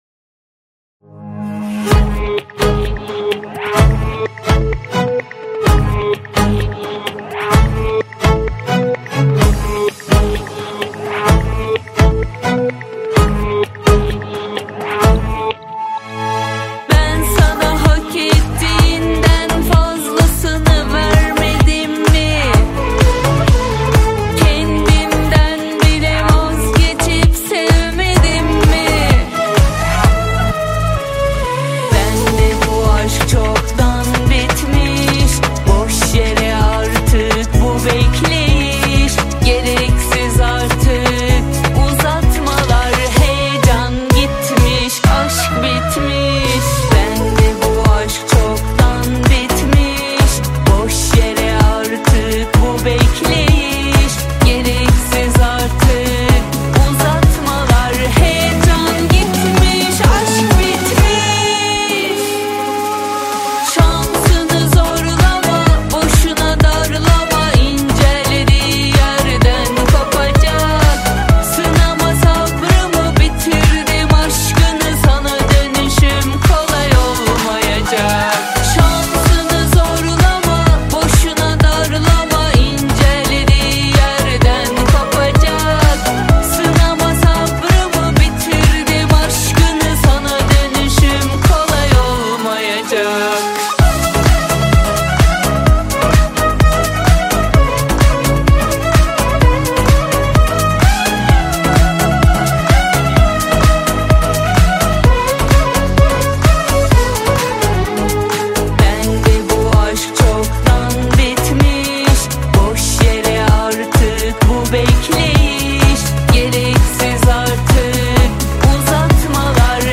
Жанр: Турецкие песни